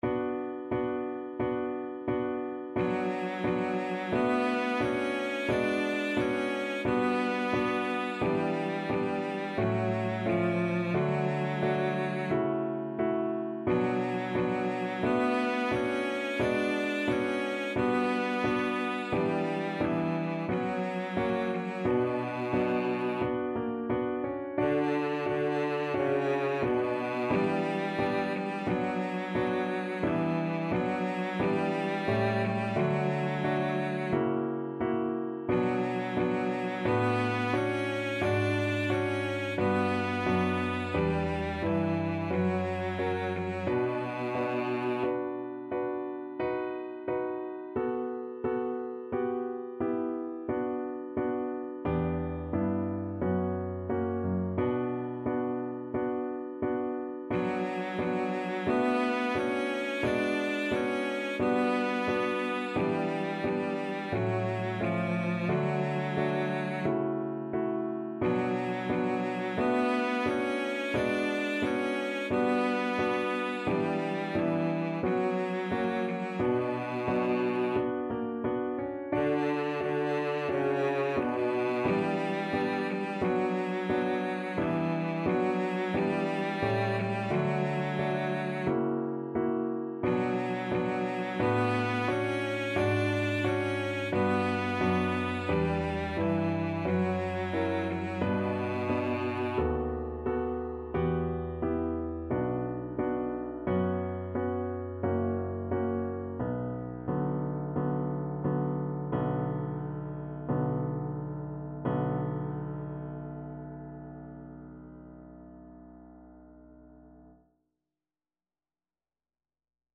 Classical Trad. Kojo no Tsuki Cello version
Cello
Traditional Music of unknown author.
D major (Sounding Pitch) (View more D major Music for Cello )
4/4 (View more 4/4 Music)
Andante =c.88